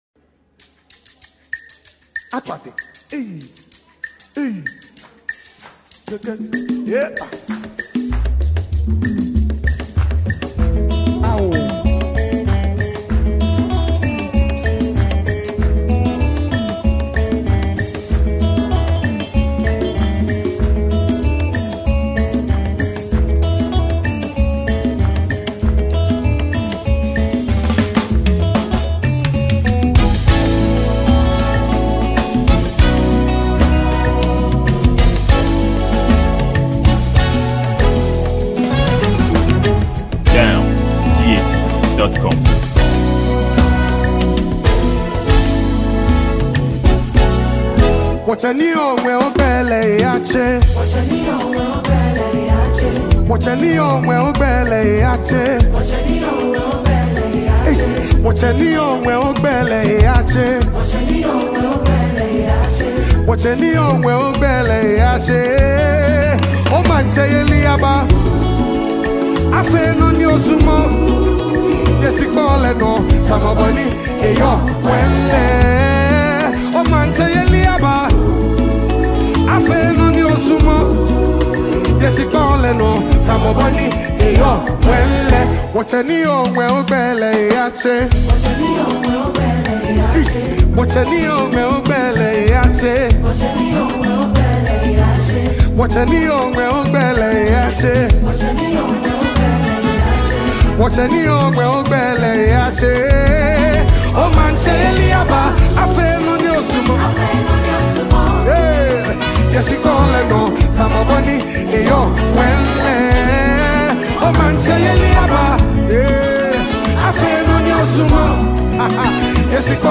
Ghana Music, Gospel
a highly classified spiritual song to all beliver in Christ.